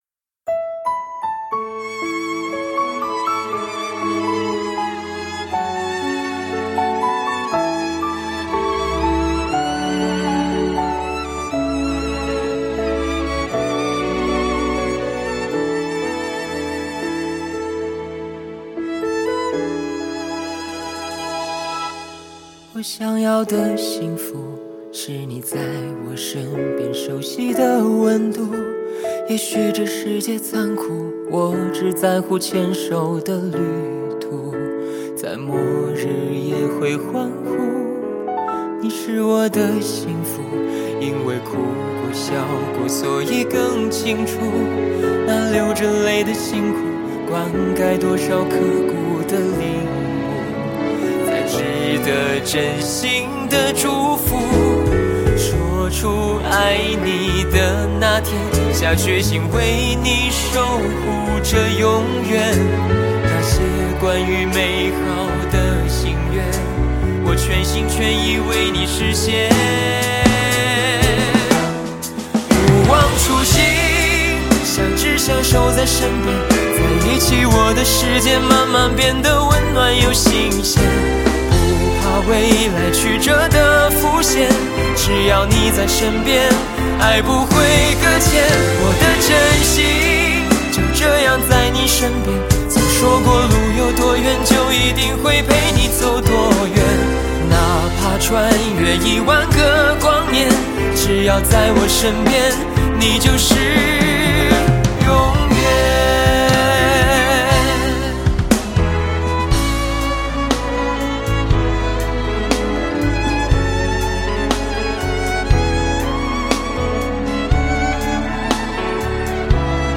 深情告白恋曲